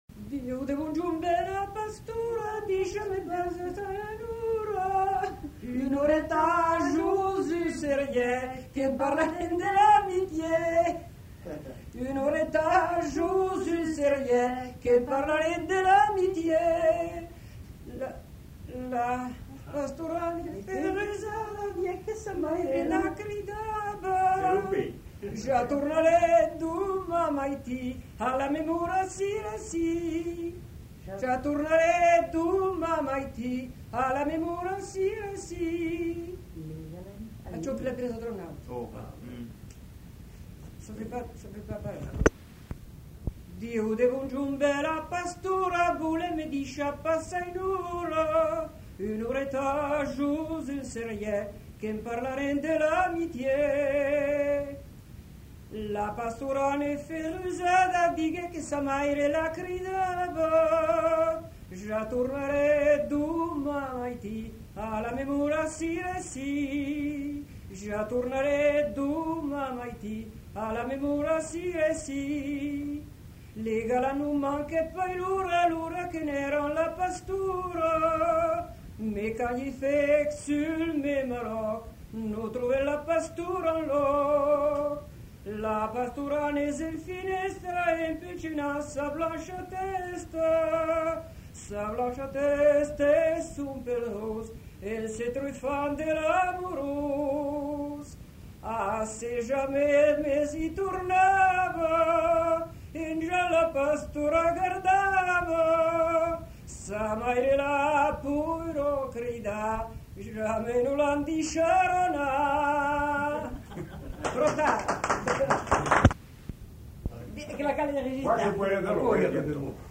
Lieu : Prat-Communal (lieu-dit)
Genre : chant
Effectif : 1
Type de voix : voix de femme
Production du son : chanté
Suivi d'un fragment d'un autre chant.